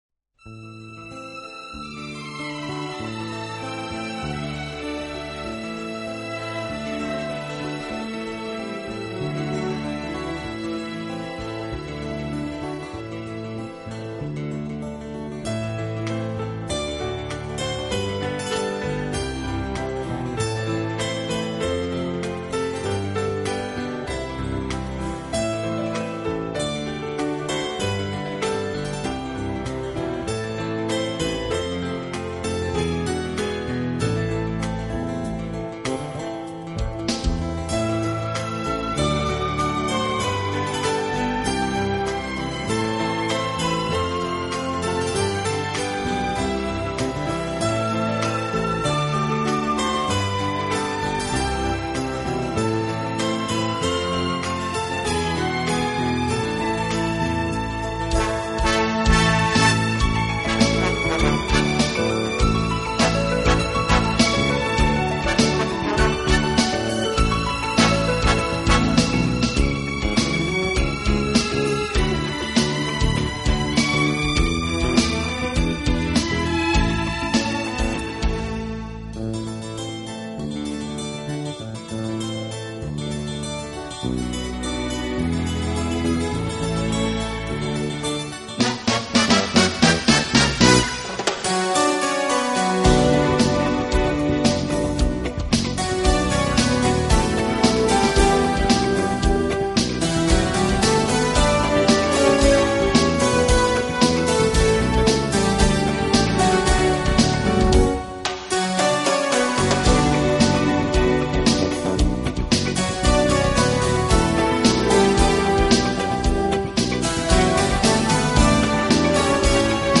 更由于其采用了美妙的轻音乐形式